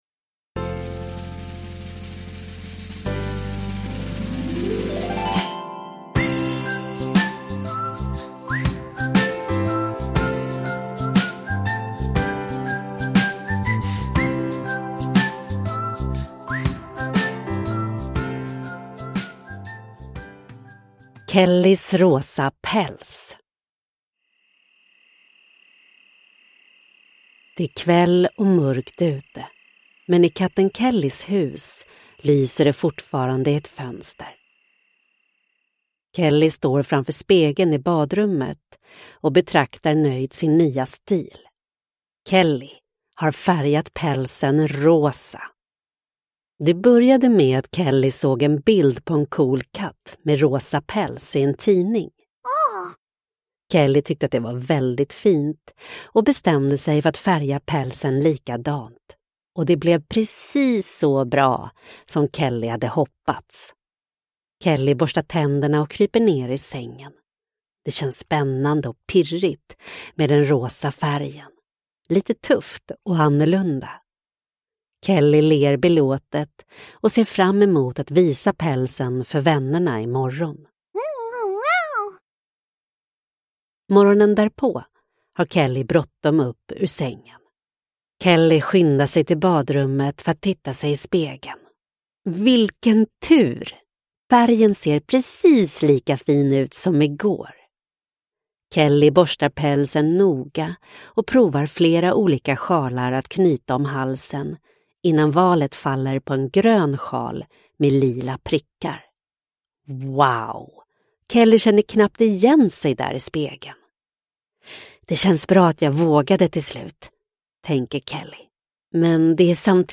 Kellys rosa päls – Ljudbok – Laddas ner